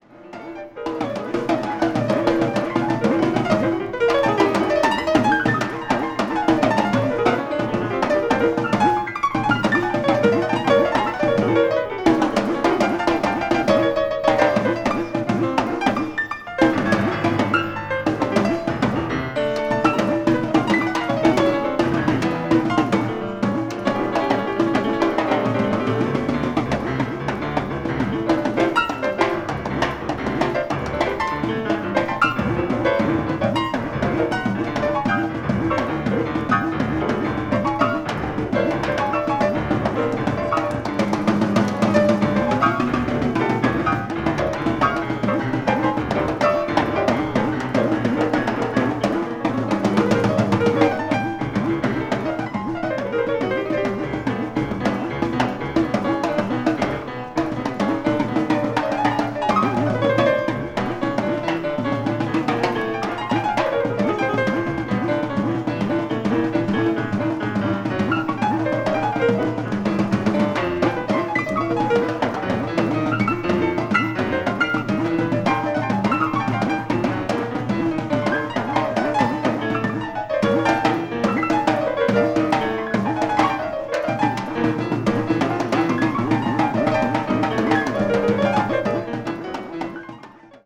1979年にニューヨークのコロンビア大学内にあるMc Millin Theatreで行ったライヴの音源を収録。
凄まじいまでにパワフルなドラミング。
avant-jazz   free improvisation   free jazz